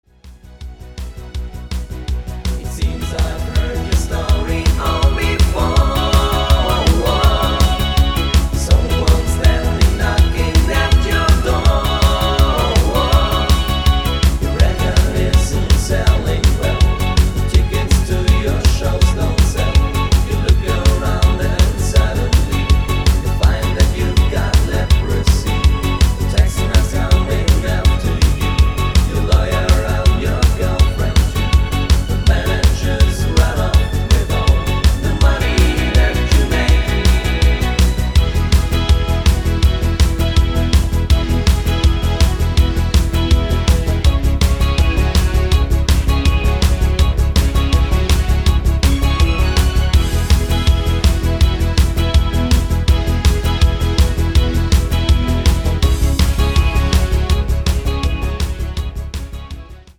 Belgischer Freestyle